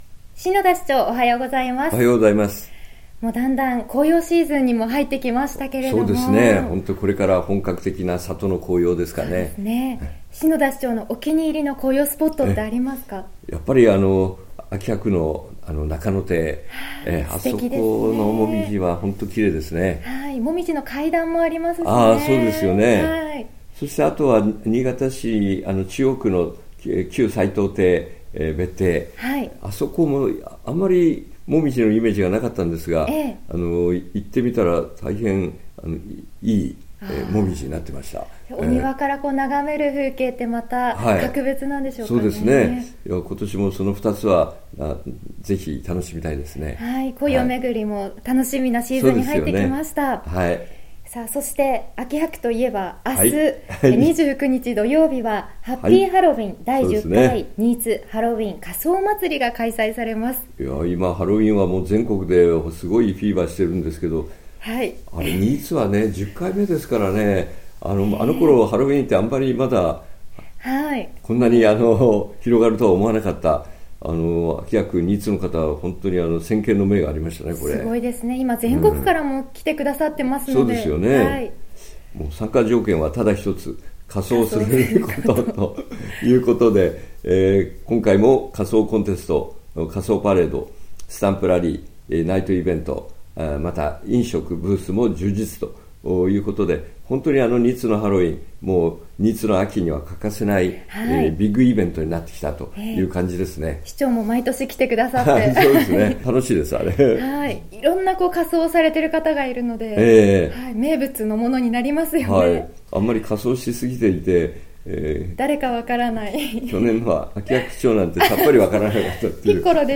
２０１６年１０月２８日（金）放送分 | 篠田市長の青空トーク